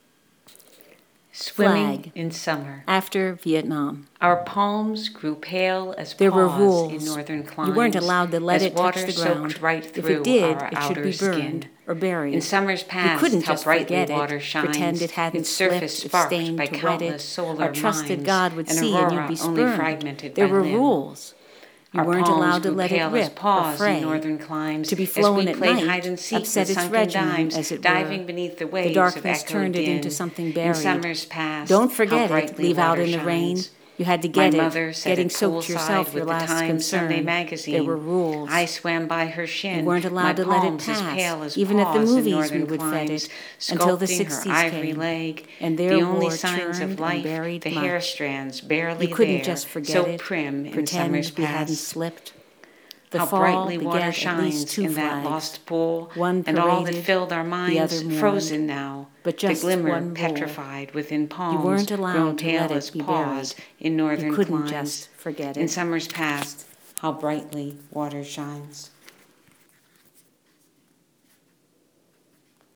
And, frankly, the Sixties – which this sound poem focuses on seems to be as modern as I will get tonight.
This is not silent! And it does give a picture of facets of modern life i.e. (i) multi-tasking, (ii)  low-tech meeting higher tech, and (iii) minds  getting stuck in grooves.